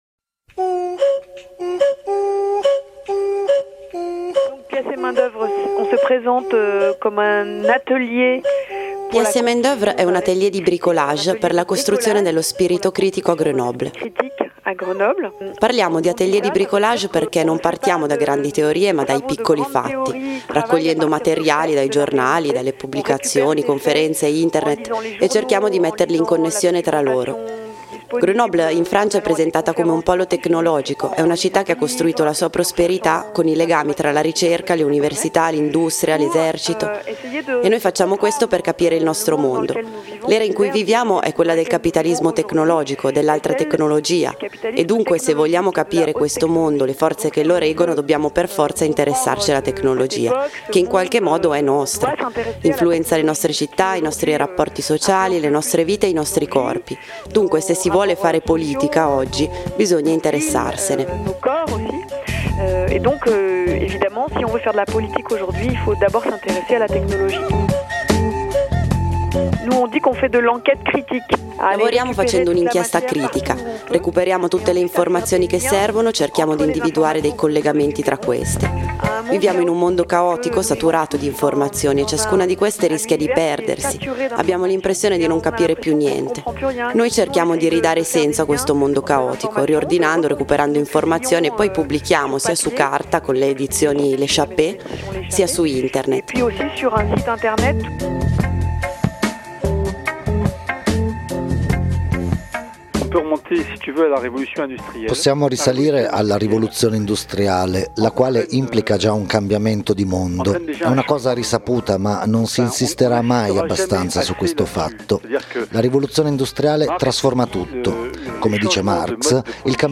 Abbiamo posto queste domande ai membri del collettivo Pièce et main d’oeuvre che da anni conducono inchieste sulla ricerca a Grenoble, punta di diamante nel settore della sperimentazione tecnologica in Francia.